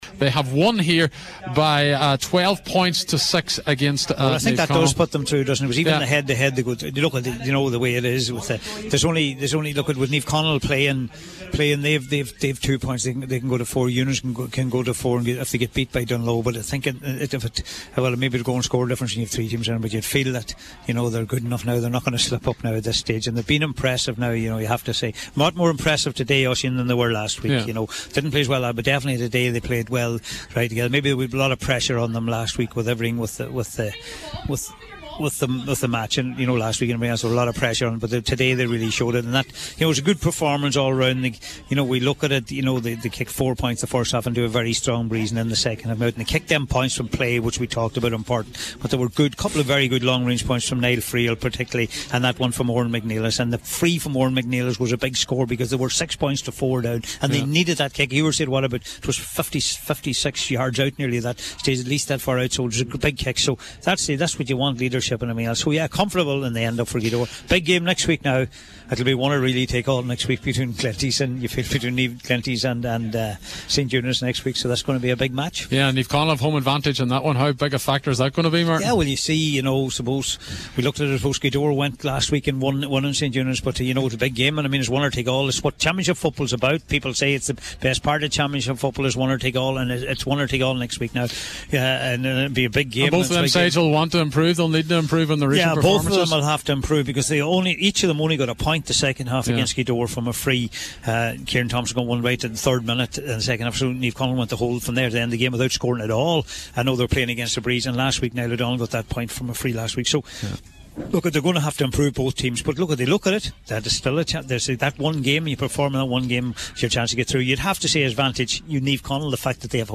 spoke about Gaoth Dobhair after the match in Magheragallon…